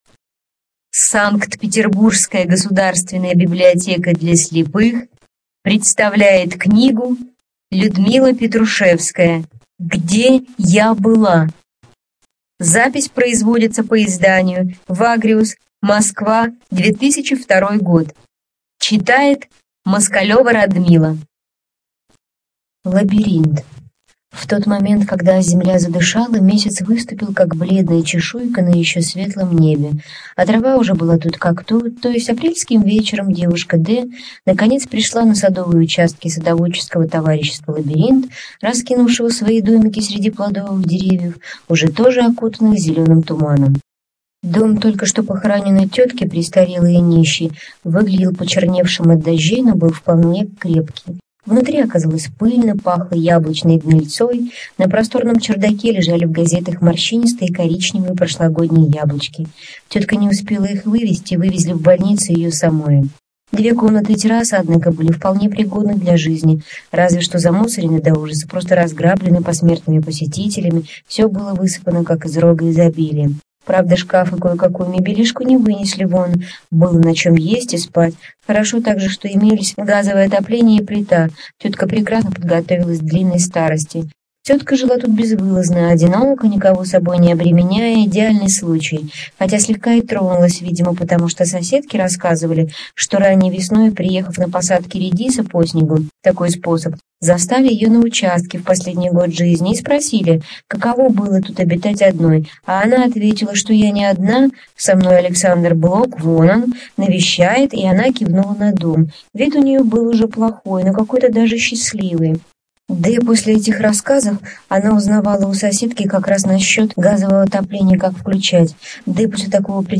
Студия звукозаписиСанкт-Петербургская государственная библиотека для слепых и слабовидящих